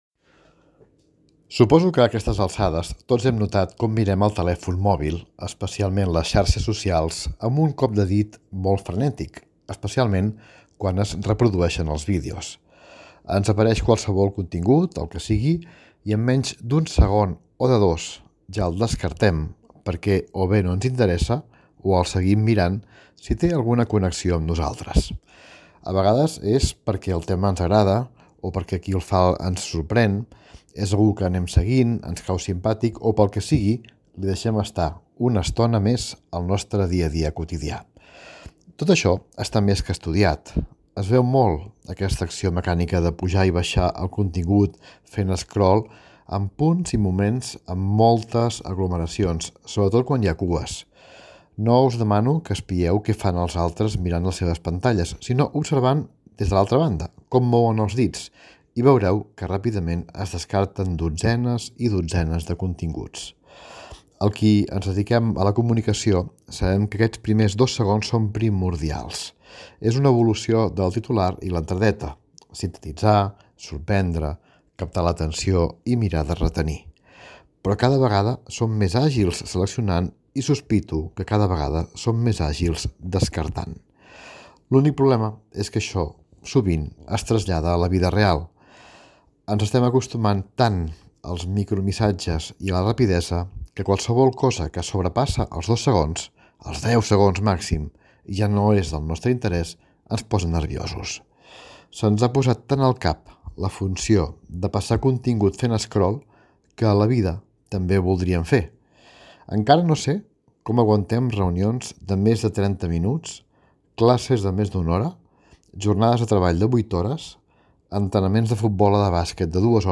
Opinió